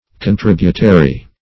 Search Result for " contributary" : The Collaborative International Dictionary of English v.0.48: Contributary \Con*trib"u*ta*ry\, a. 1.